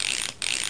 reelclic.mp3